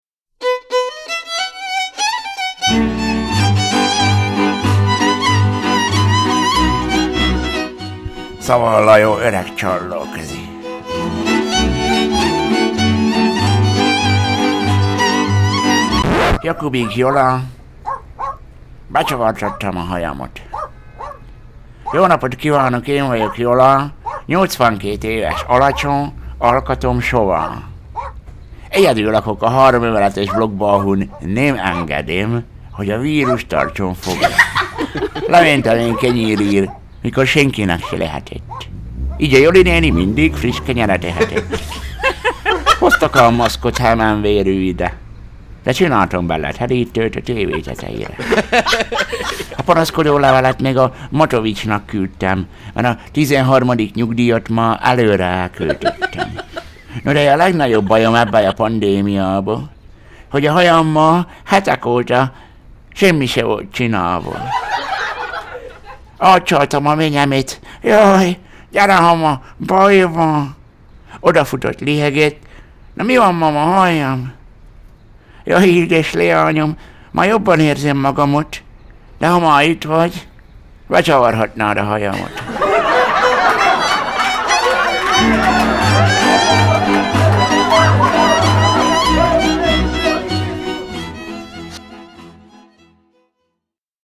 Szaval a jó öreg csallóközi: Becsavartattama hajamot!